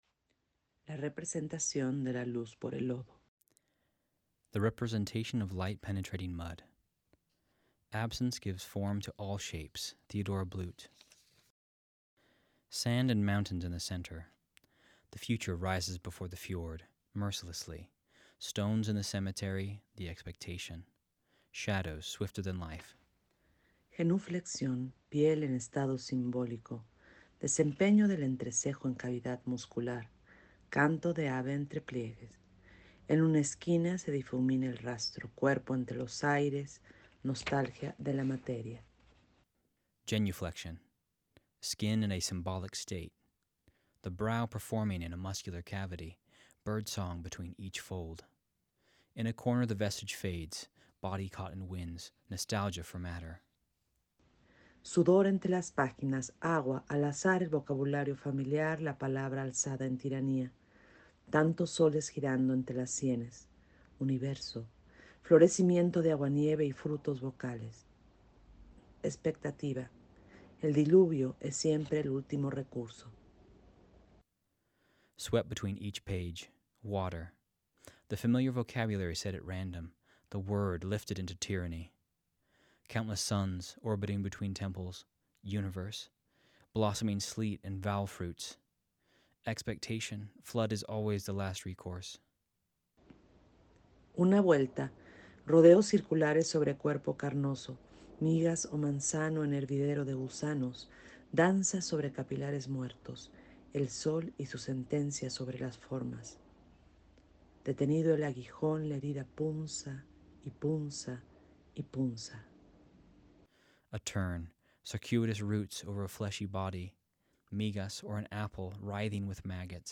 The-Representation-of-Mud-Spanish-and-English-Alternating.mp3